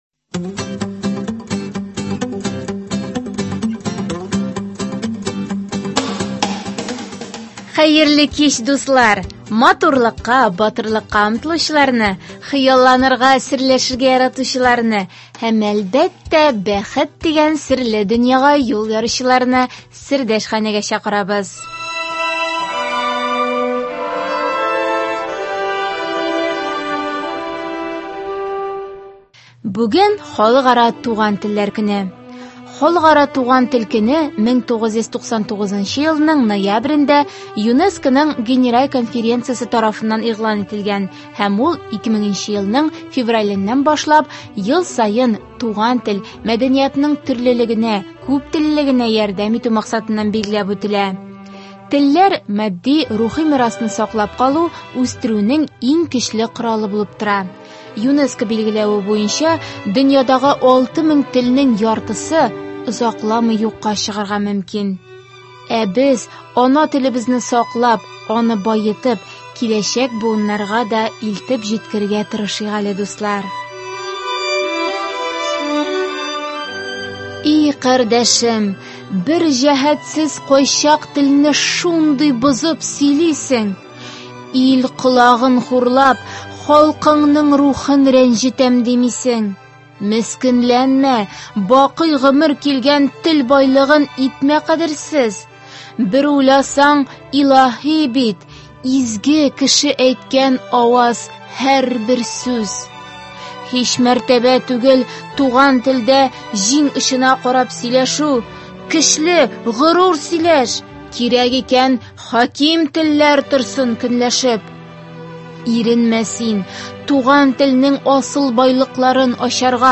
Бергәләп тәрбия кылганда гына ана телебез, матур традицияләребез, сәнгатебез югалмый, онытылмый. Тапшыруыбыз кунагы да тәрбия гаиләдән башлана, ди.